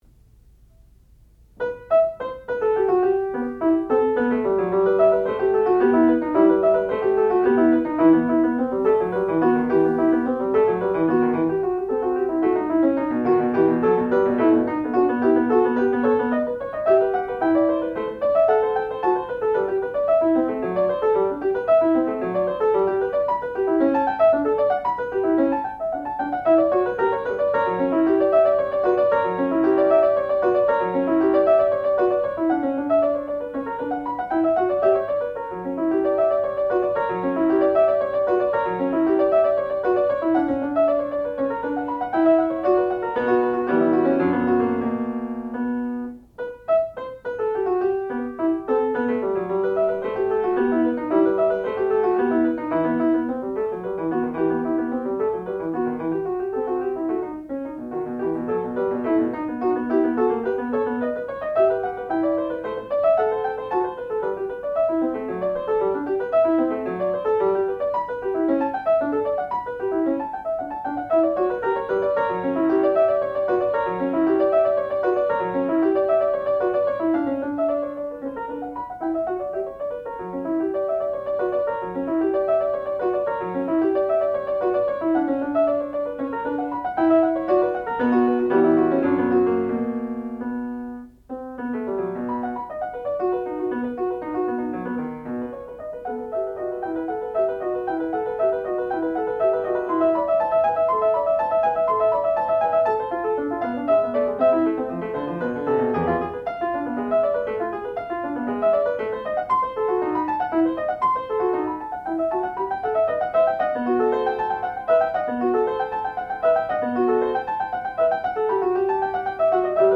sound recording-musical
classical music
Advanced Recital
piano